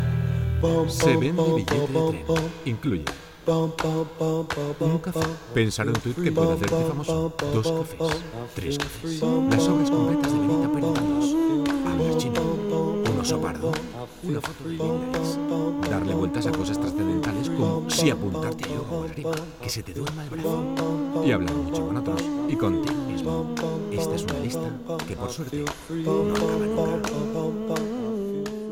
Annonces
Voz neutral, emotiva, energética y divertida
Profesional Studio at home